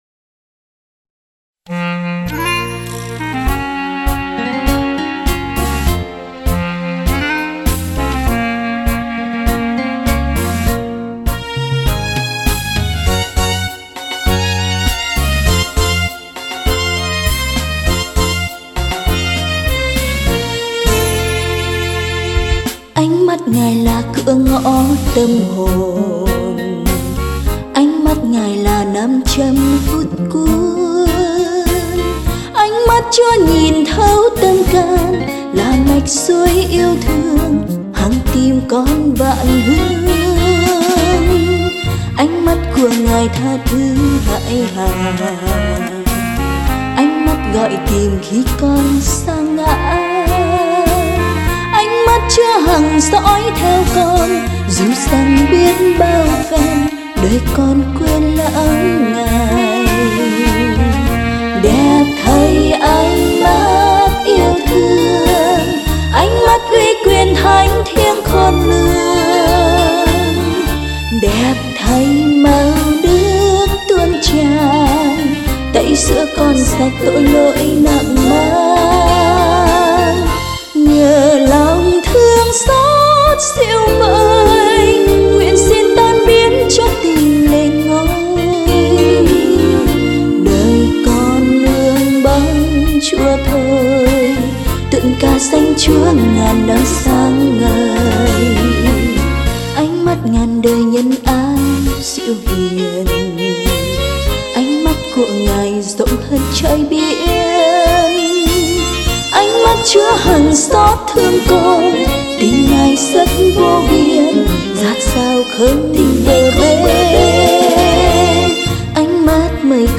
Thánh Ca: